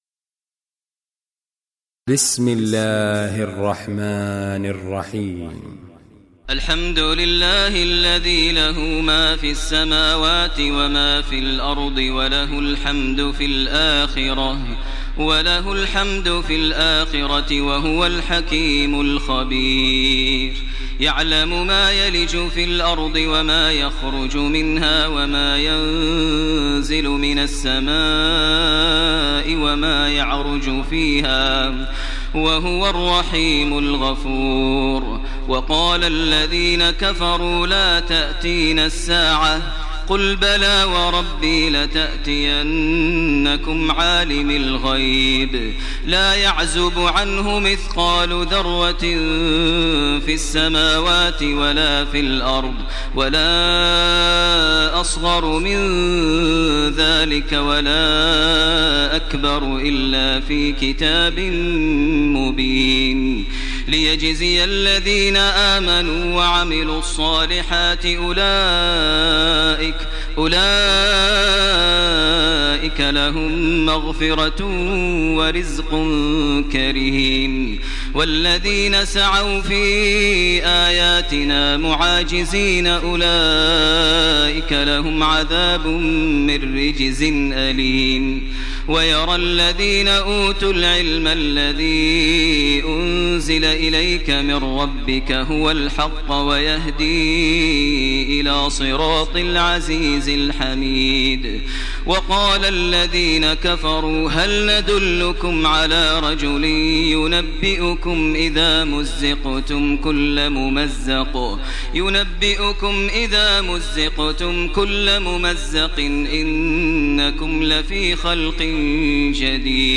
Surah Saba Recitation by Sheikh Maher al Mueaqly
Surah Saba, listen online mp3 tilawat / recitation in the voice of Sheikh Maher al Mueaqly.